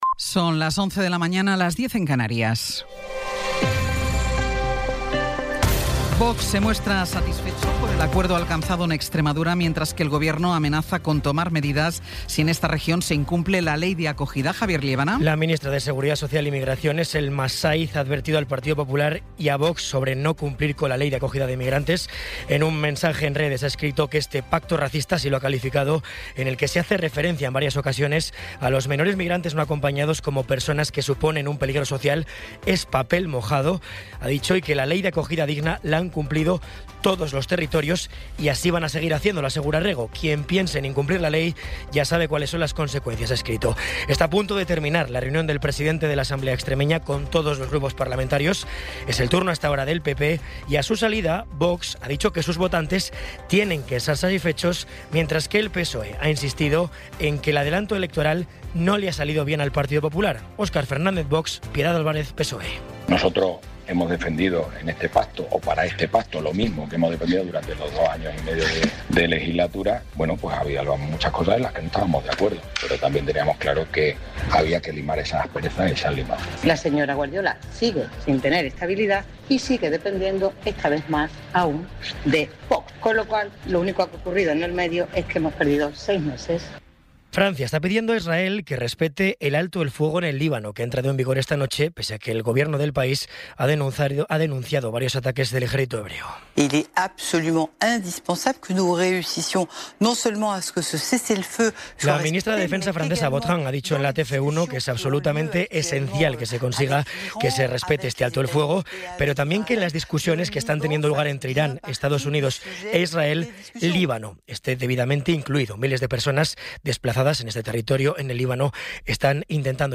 Resumen informativo con las noticias más destacadas del 17 de abril de 2026 a las once de la mañana.